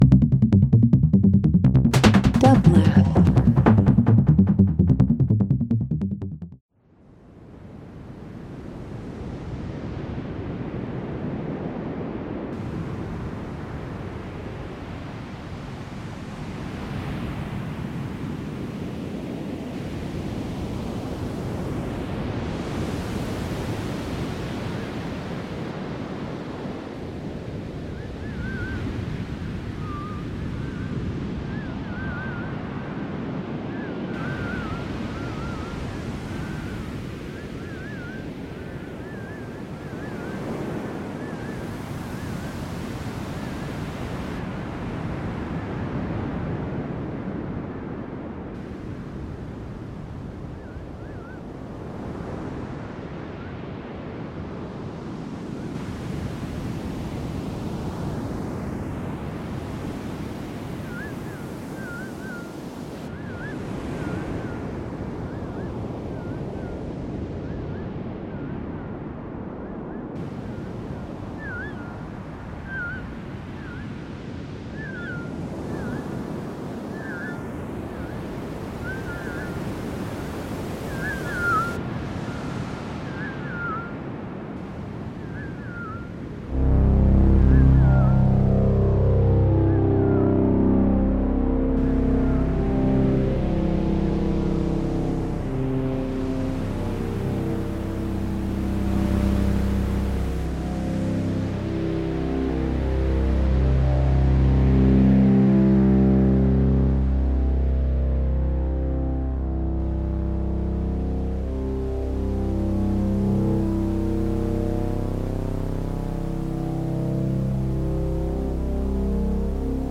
Experimental Live Performance Synth